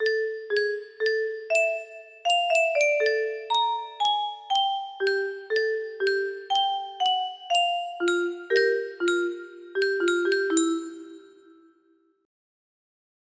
Unknown Artist - Untitledasdasdasdasd music box melody